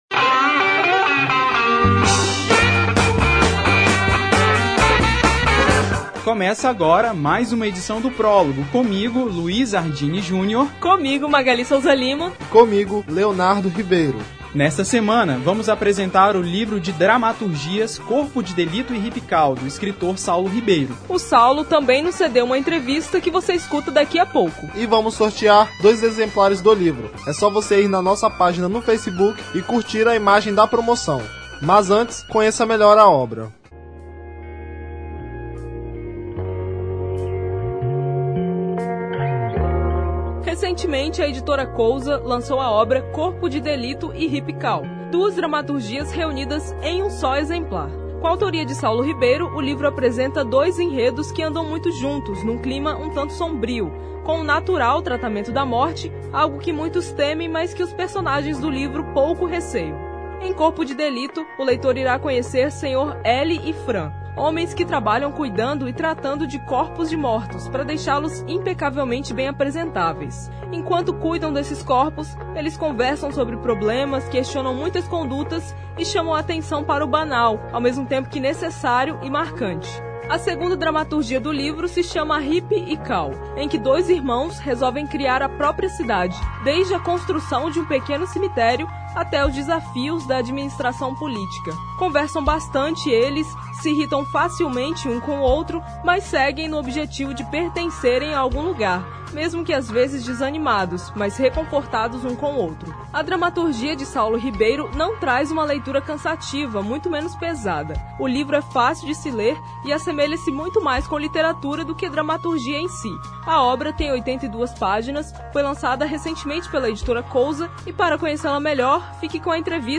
uma entrevista